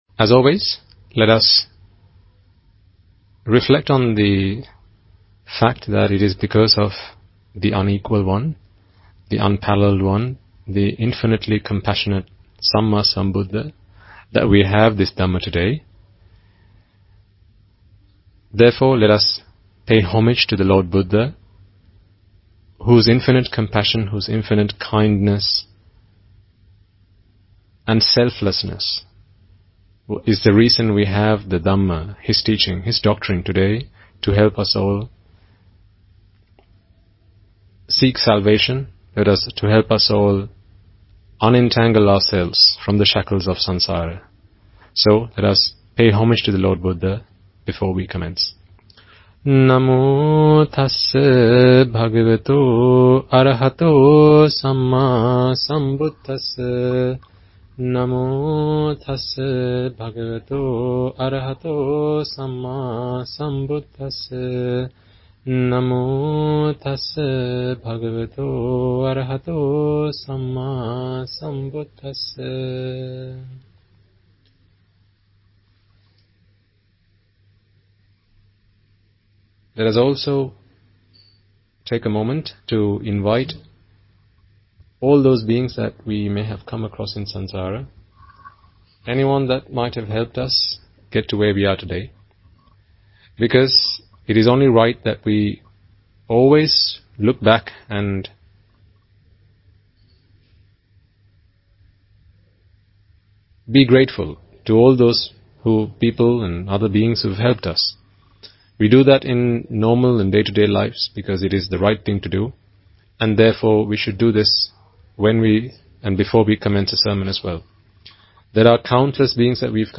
Sermon
English Dhamma Sermon on